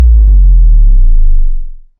Bass Drop Hit
A punchy electronic bass drop impact with tight low-end and quick sustain
bass-drop-hit.mp3